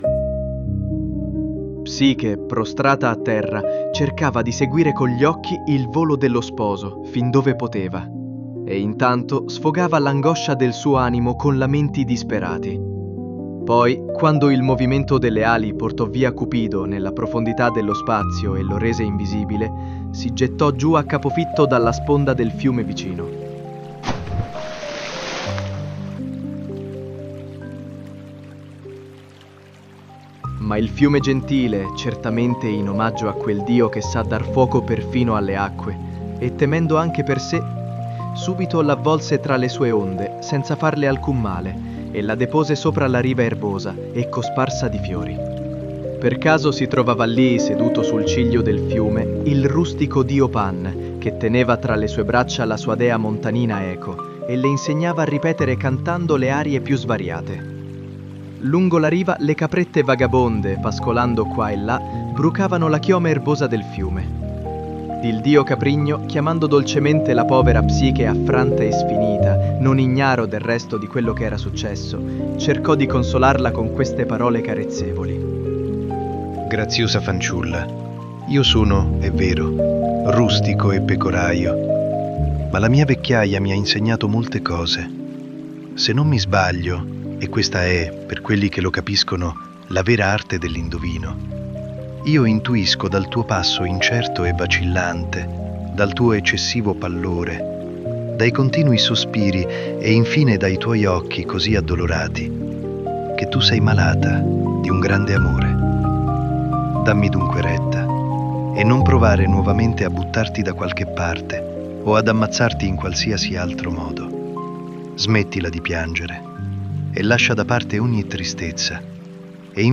La colonna sonora comprende la Sarabanda HWV 437 di Haendel nella celebre versione di "Barry Lyndon".
The soundtrack includes Handel’s Sarabande HWV 437 in the famous version from *Barry Lyndon*.